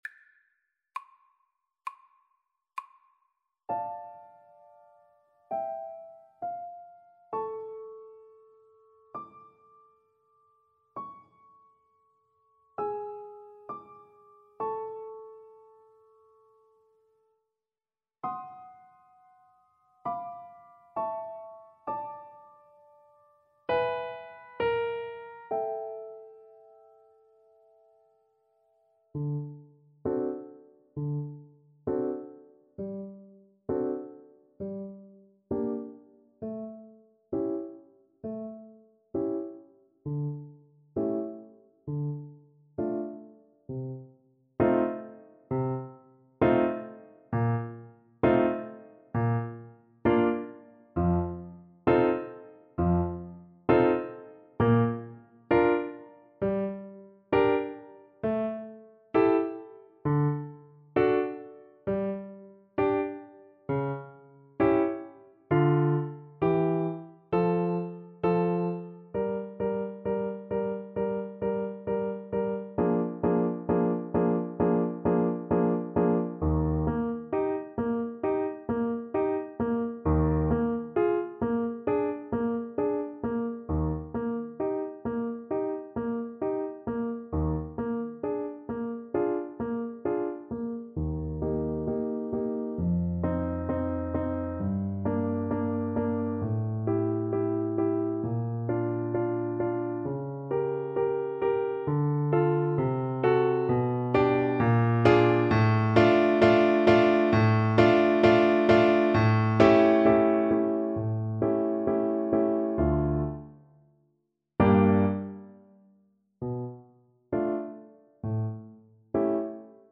Play (or use space bar on your keyboard) Pause Music Playalong - Piano Accompaniment Playalong Band Accompaniment not yet available reset tempo print settings full screen
D minor (Sounding Pitch) A minor (French Horn in F) (View more D minor Music for French Horn )
Andante =66
Classical (View more Classical French Horn Music)